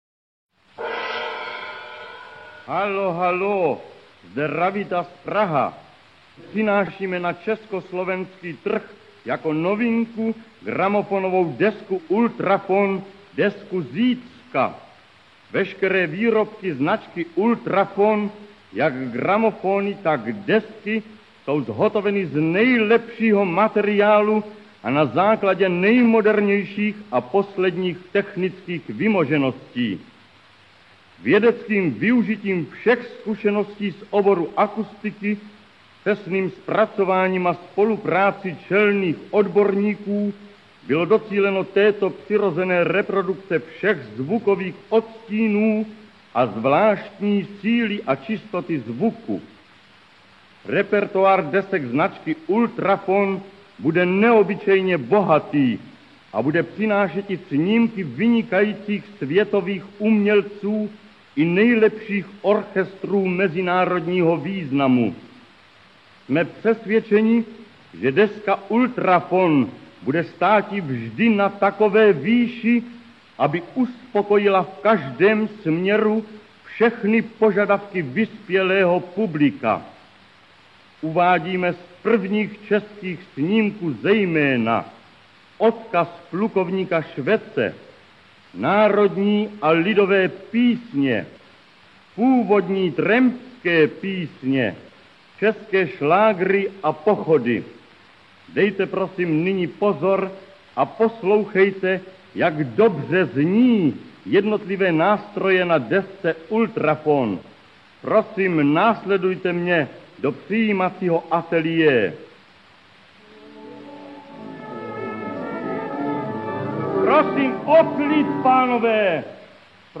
foxtrot
Obe nahrávky vznikli v novembri 1929 v Berlíne.